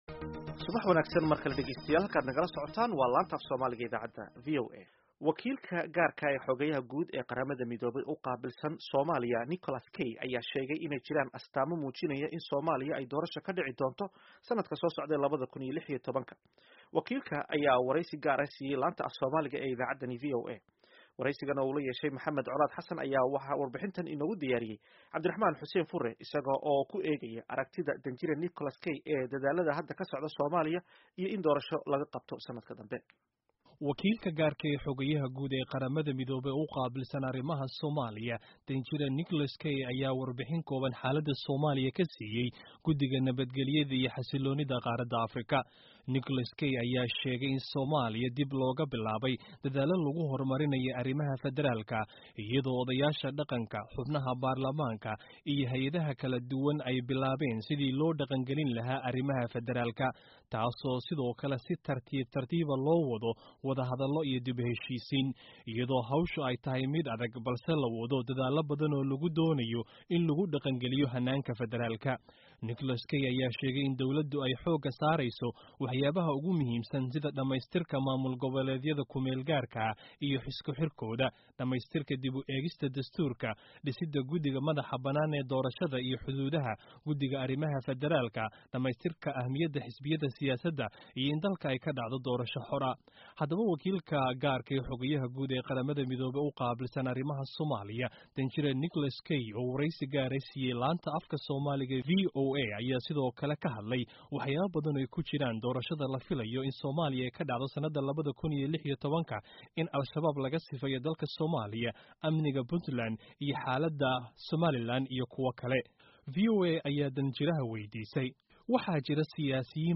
Wareysi: Nicholas Kay Ergeyga Q.M ee Somalia